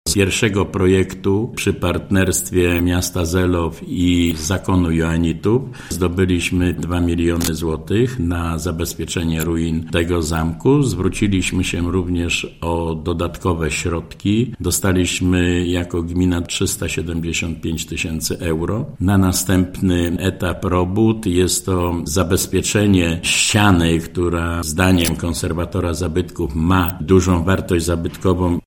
– Koncentrujemy się obecnie na jednej, ale wyjątkowej ścianie wewnętrznej zamku – mówi Janusz Krzyśków, wójt Słońska: